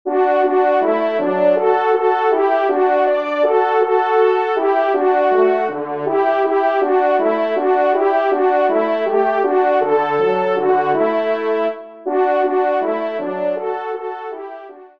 Pièce d’un lot de 8 Compositions pour Duos de Cors ou de Trompes de chasse